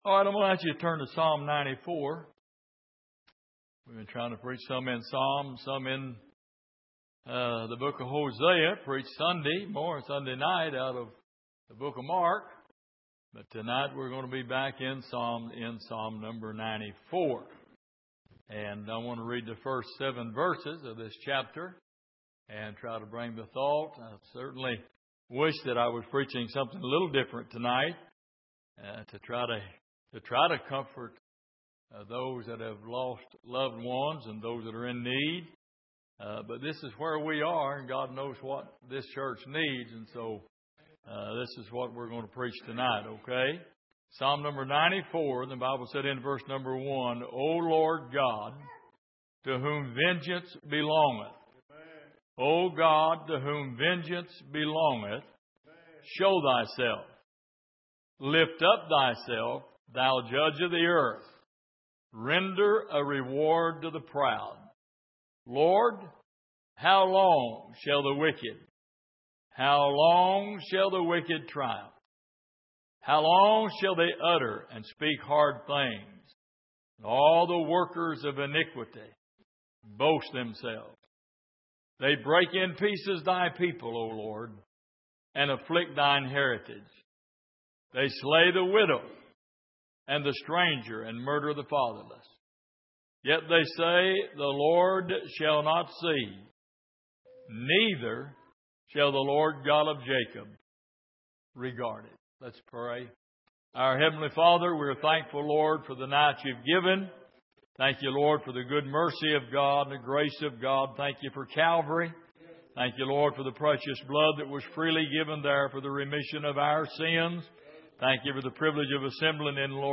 Exposition of the Psalms Passage: Psalm 94:1-7 Service: Midweek Man’s Cry For Justice « Does Jesus Care?